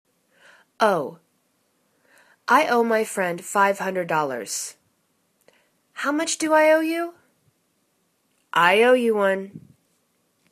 owe     /o:/    v